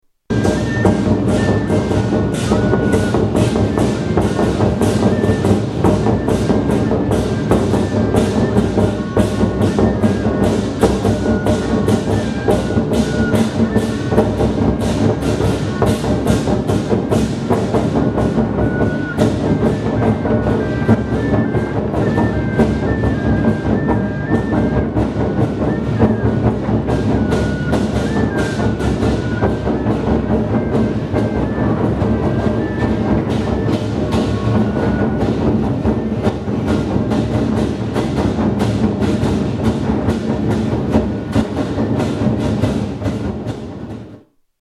Melancholy bee crack at Lantern Festival